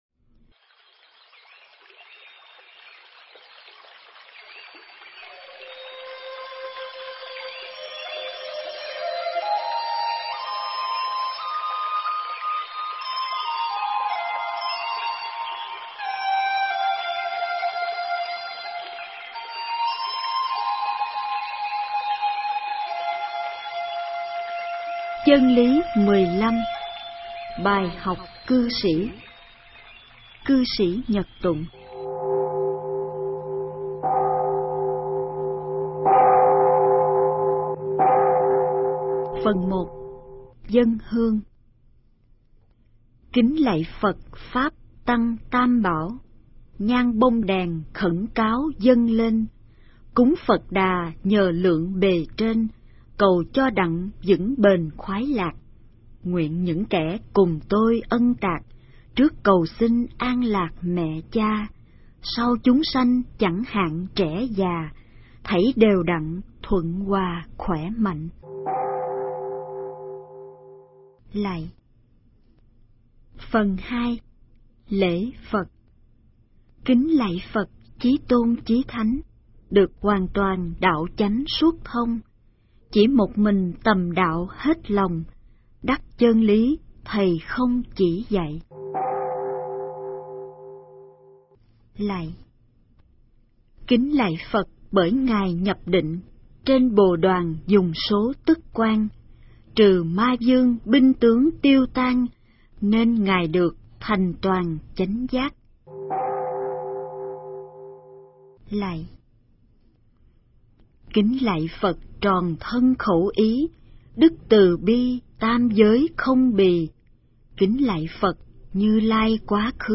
Nghe sách nói chương 15. Bài Học Cư Sĩ (Cư Sĩ Nhật Tụng)